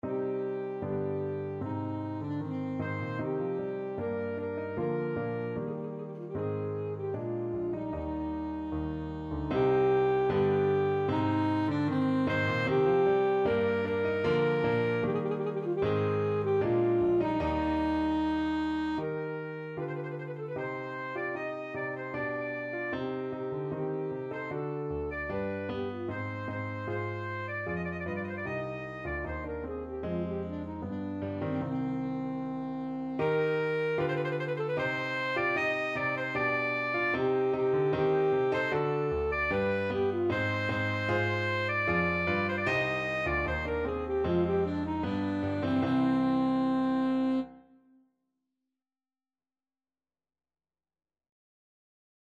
Alto Saxophone
Steadily =c.76
C minor (Sounding Pitch) A minor (Alto Saxophone in Eb) (View more C minor Music for Saxophone )
2/2 (View more 2/2 Music)
Classical (View more Classical Saxophone Music)